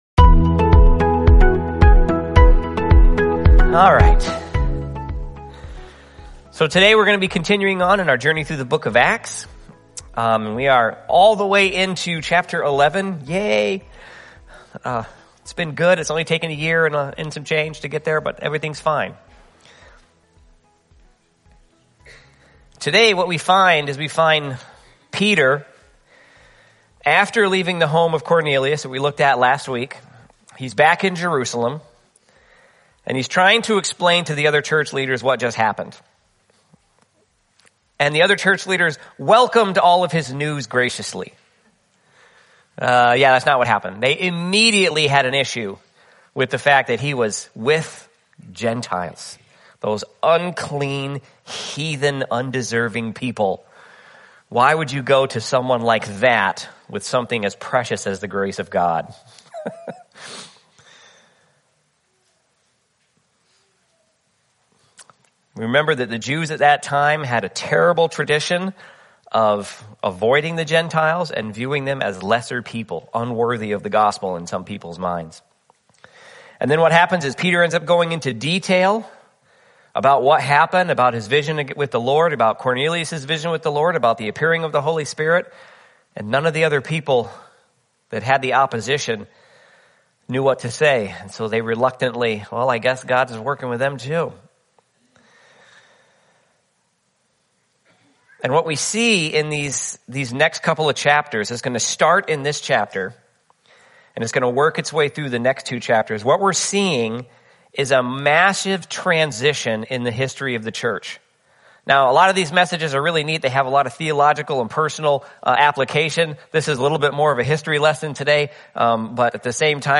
Sermons | River of Life Fellowship Church